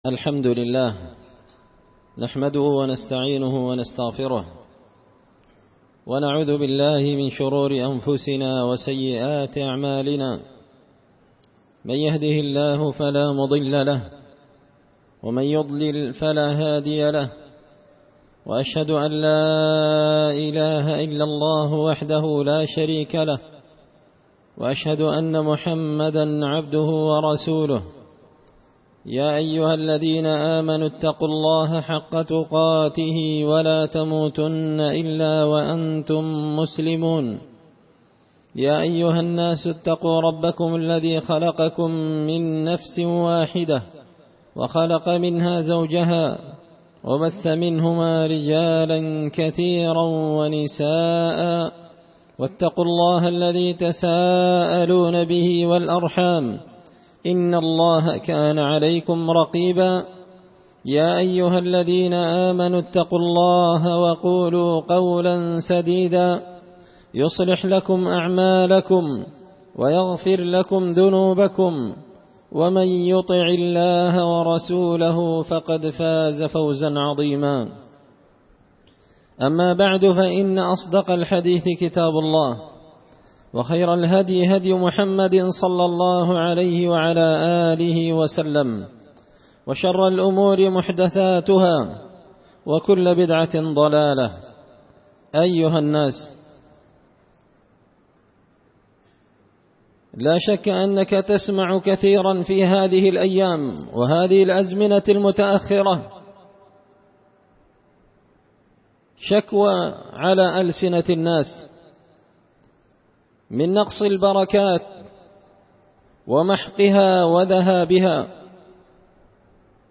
خطبة جمعة بعنوان – أسباب زوال البركة
دار الحديث بمسجد الفرقان ـ قشن ـ المهرة ـ اليمن